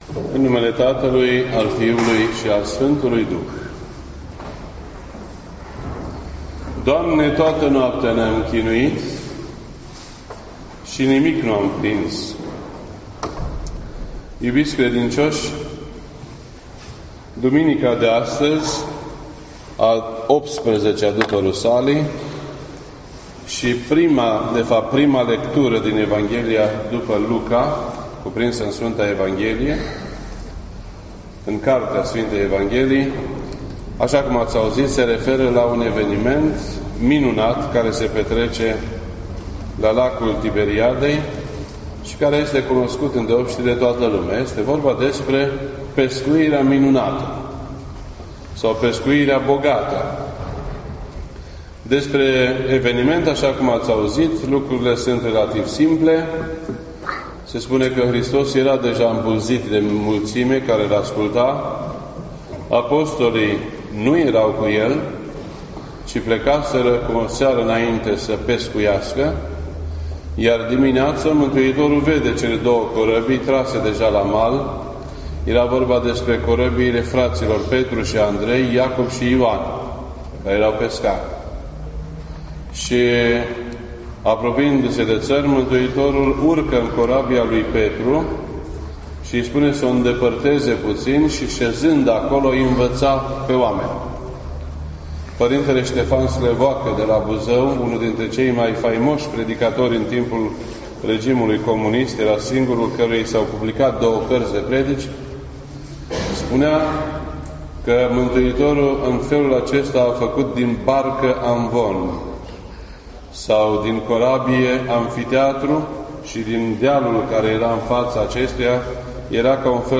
This entry was posted on Sunday, September 24th, 2017 at 7:07 PM and is filed under Predici ortodoxe in format audio.